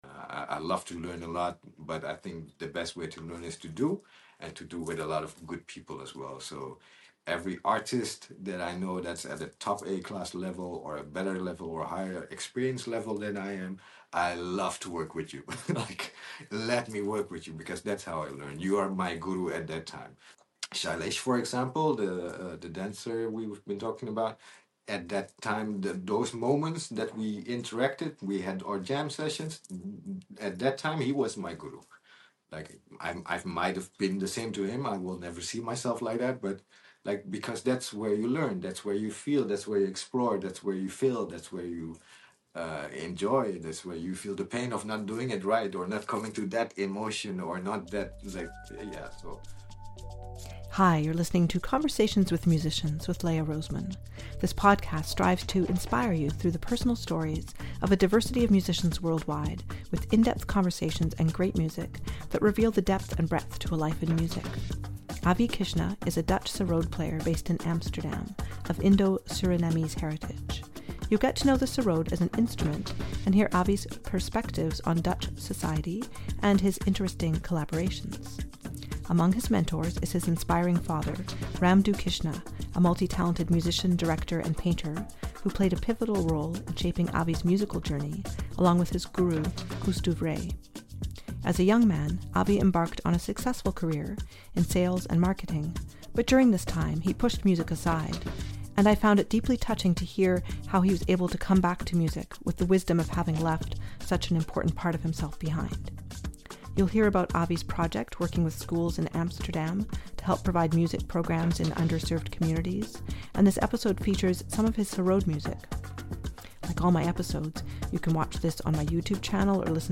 sarod